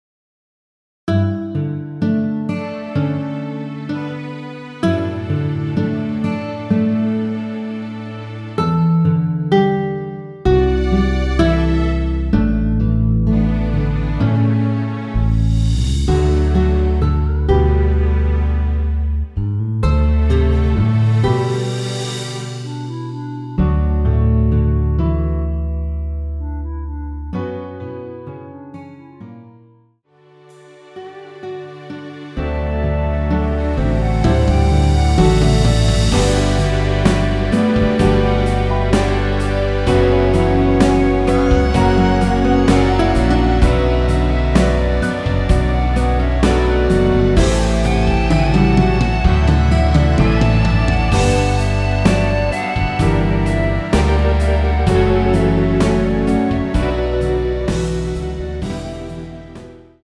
원키에서(-6)내린 멜로디 포함된 MR입니다.
D
앞부분30초, 뒷부분30초씩 편집해서 올려 드리고 있습니다.
중간에 음이 끈어지고 다시 나오는 이유는